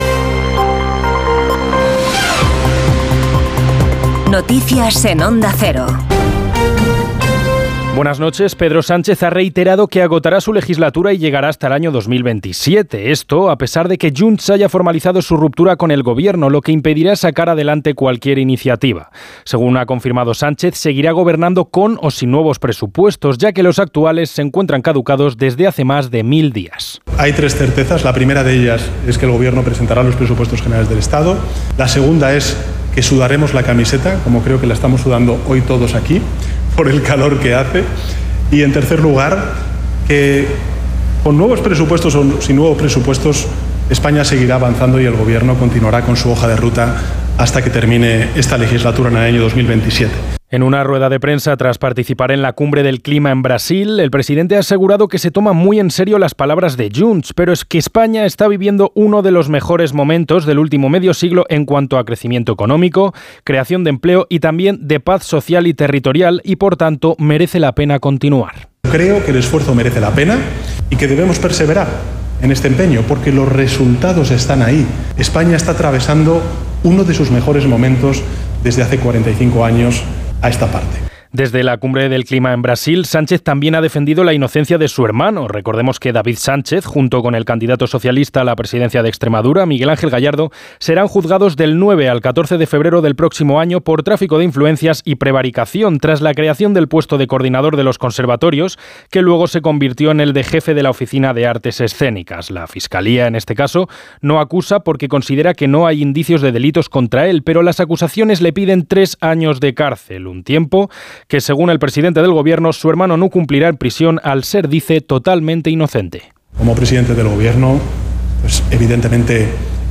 Las noticias en Onda Cero